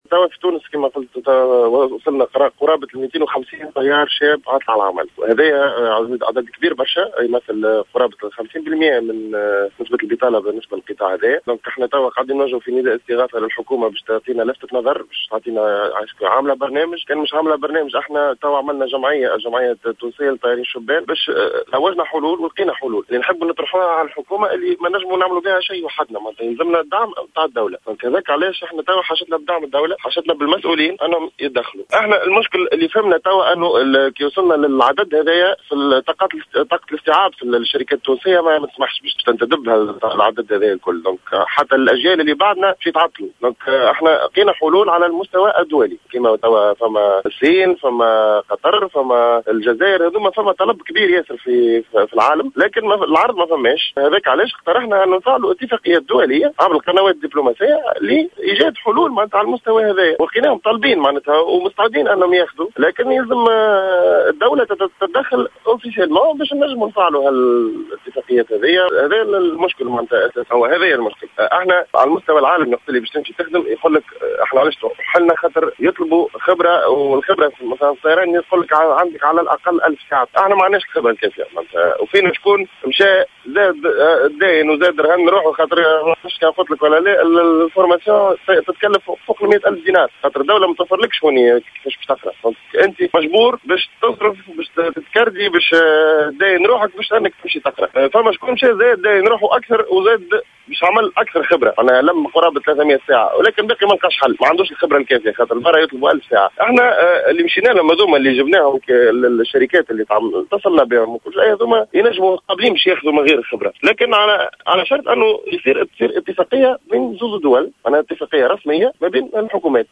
a déclaré ce jeudi 21 janvier 2016 dans une intervention sur les ondes de Jawhara FM, que le nombre de jeunes pilotes en chômage a atteint les 250 personnes, soit 50% du nombre total des chômeurs dans ce secteur.